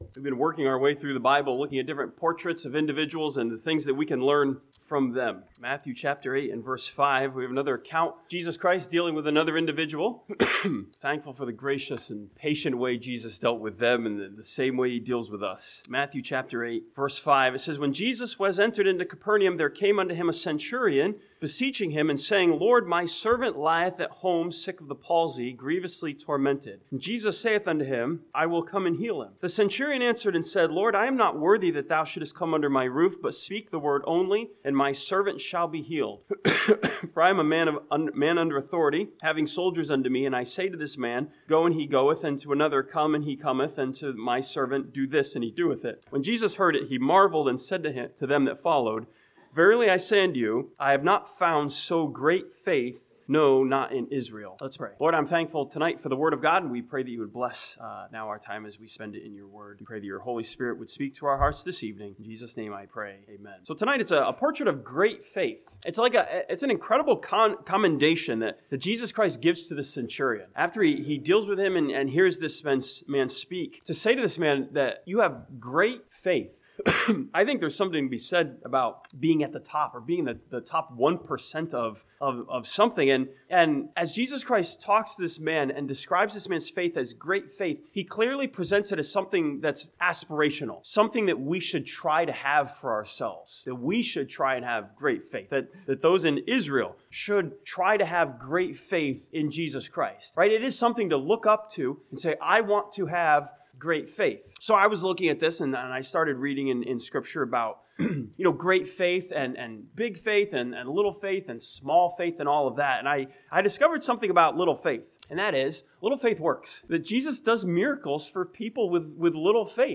This sermon from Matthew chapter 8 challenges us with a portrait of great faith in a centurion who showed great humility and respect.